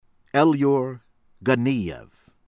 AZIMOV, RUSTAM roos-TAHM    ah-ZEE-mawf